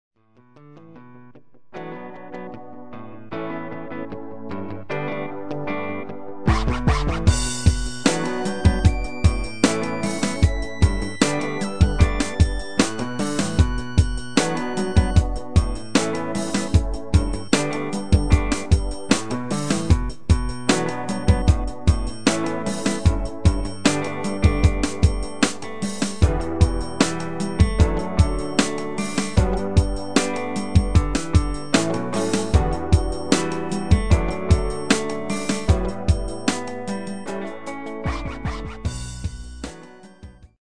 Demo/Koop midifile
Genre: R&B / Soul / Funk
- Vocal harmony tracks
Demo's zijn eigen opnames van onze digitale arrangementen.